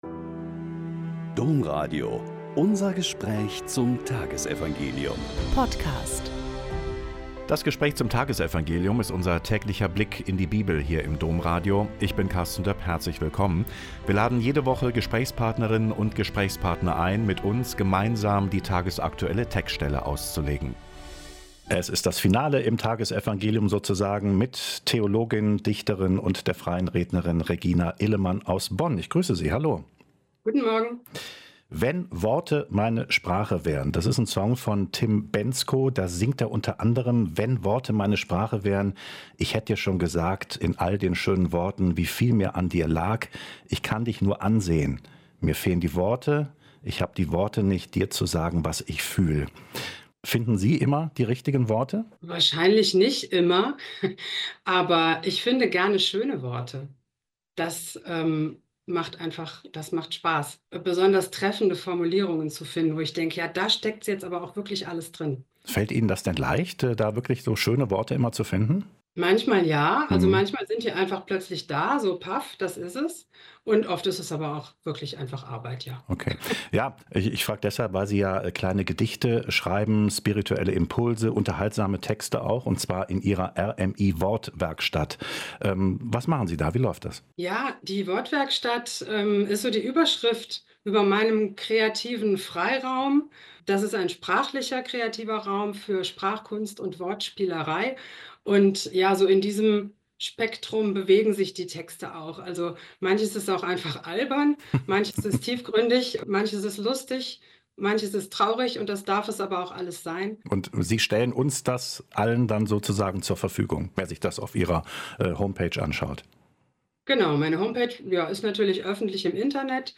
Lk 15,1-3.11-32 - Gespräch